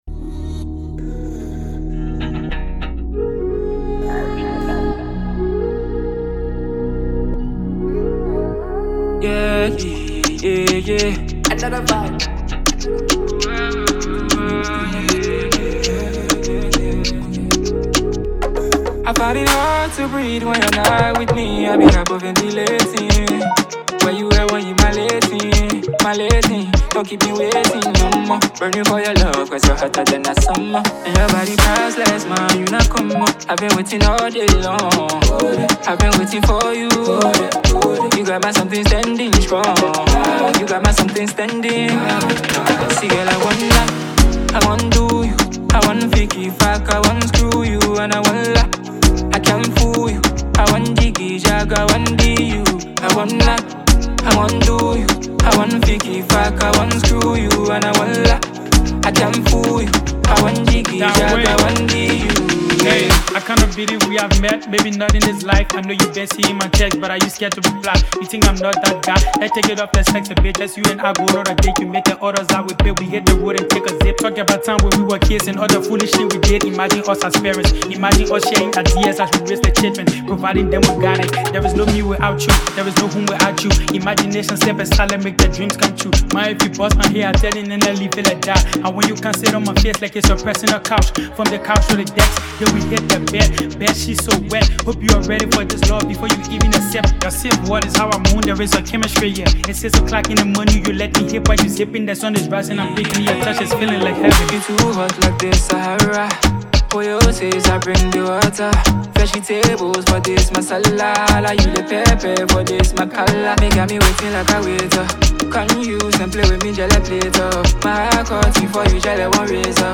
With its raw lyrics and infectious energy